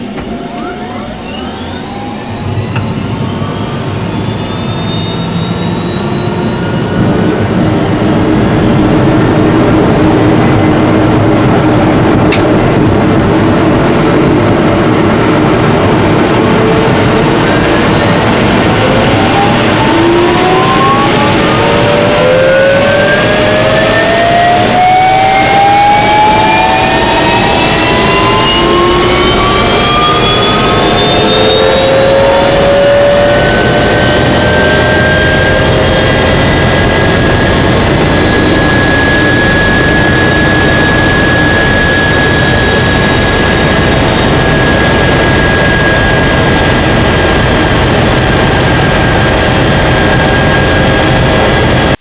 ec135_turbine_strt_in.wav